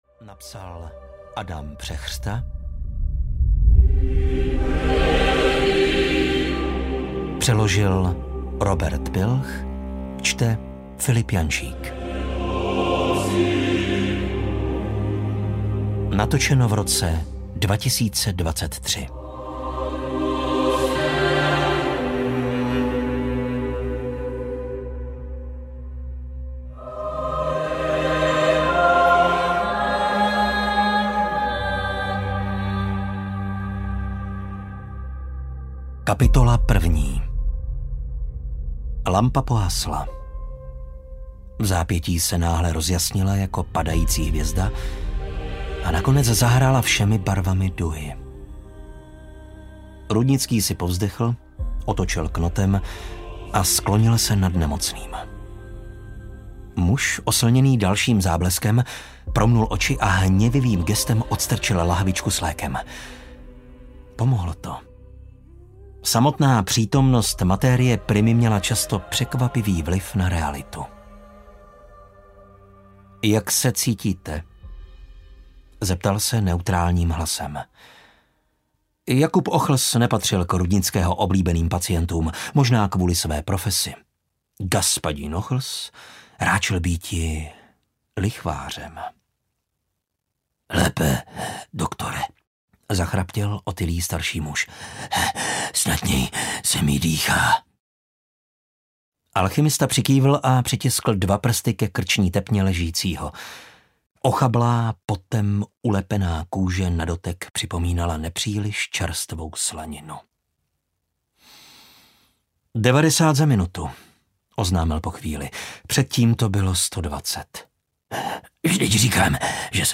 Stín audiokniha
Ukázka z knihy